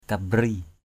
/ka-ɓri:/ aiek: ‘cambri’ cO}